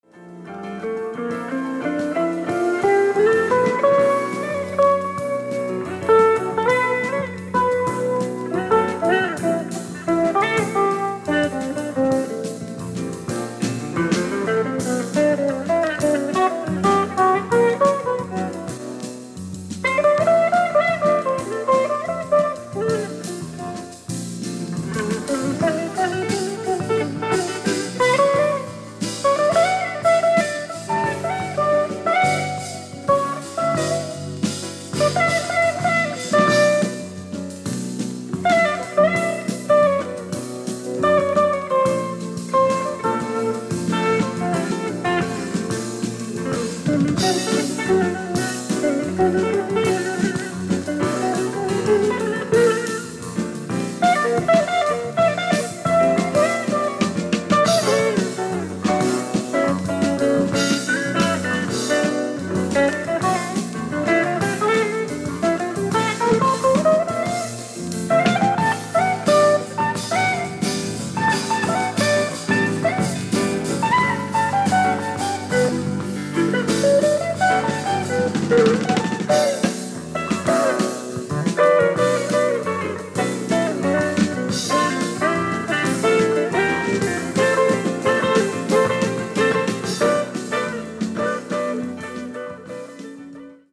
Bass
Drums
Piano, Synthesizer
Guitar